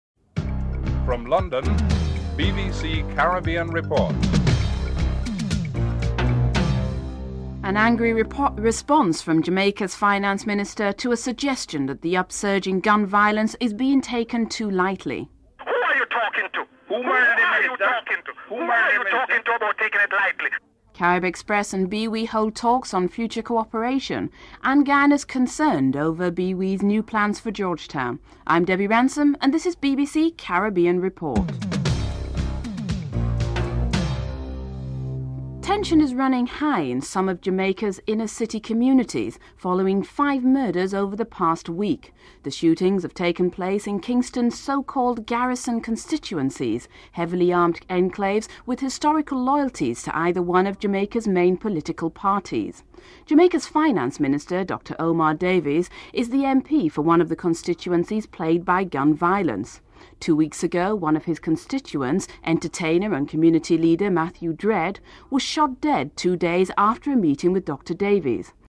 In this report, Jamaica's Finance Minister, Omar Davies responds angrily to suggestions that the upsurge of gun violence in Jamaica is being taken too lightly.